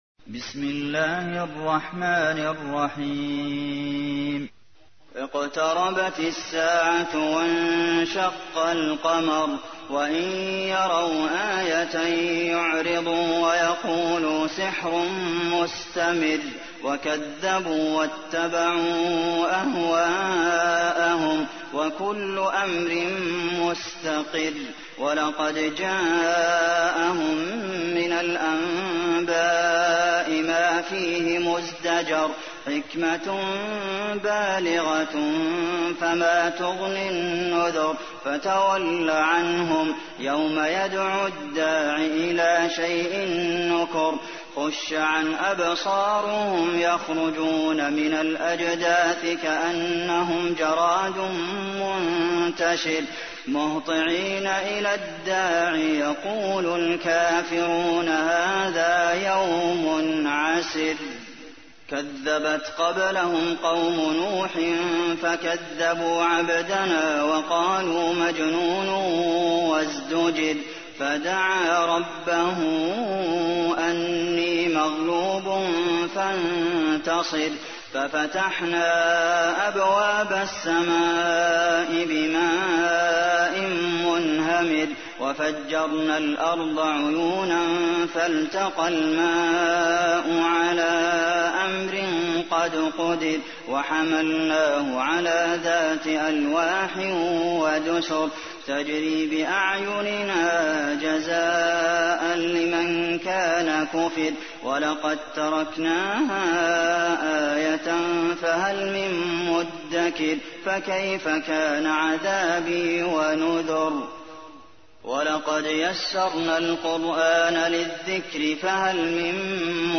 تحميل : 54. سورة القمر / القارئ عبد المحسن قاسم / القرآن الكريم / موقع يا حسين